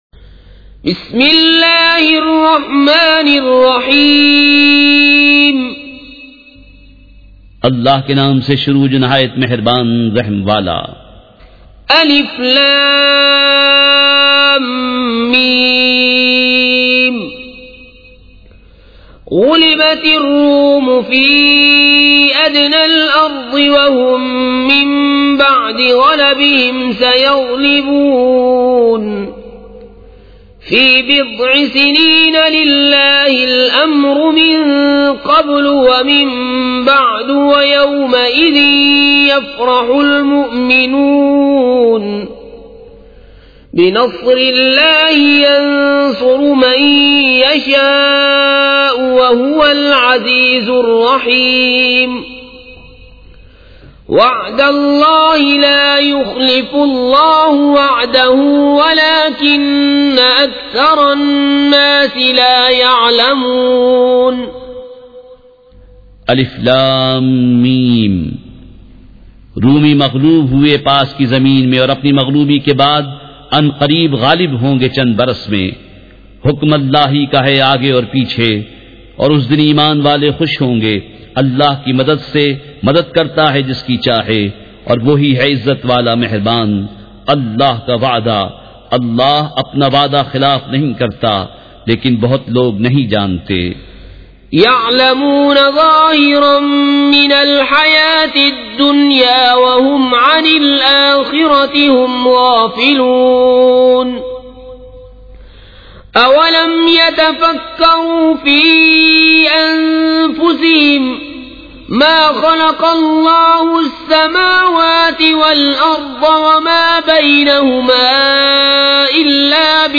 سورۃ الروم مع ترجمہ کنزالایمان ZiaeTaiba Audio میڈیا کی معلومات نام سورۃ الروم مع ترجمہ کنزالایمان موضوع تلاوت آواز دیگر زبان عربی کل نتائج 1815 قسم آڈیو ڈاؤن لوڈ MP 3 ڈاؤن لوڈ MP 4 متعلقہ تجویزوآراء
surah-ar-room-with-urdu-translation.mp3